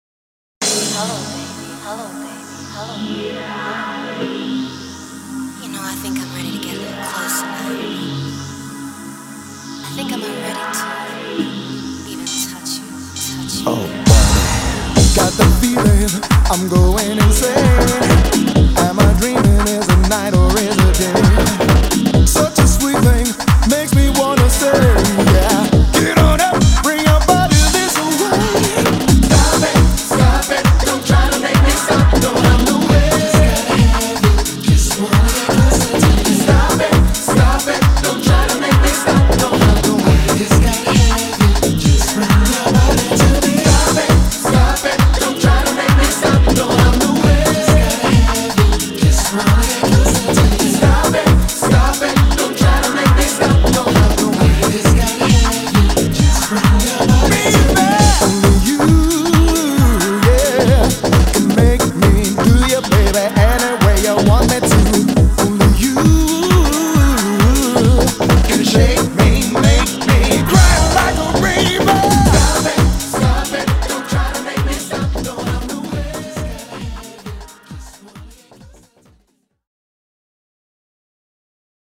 BPM133
Audio QualityPerfect (High Quality)
Regardless, it's a groovy song.